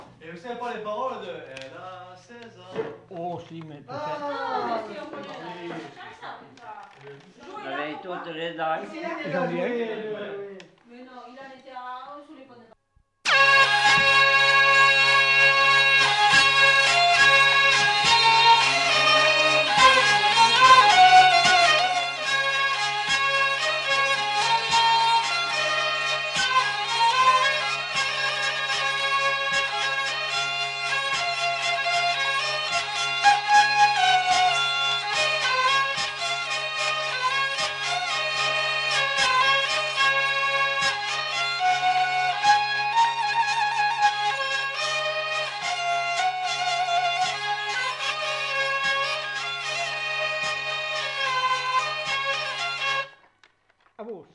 Lieu : Vielle-Soubiran
Genre : morceau instrumental
Instrument de musique : vielle à roue
Danse : valse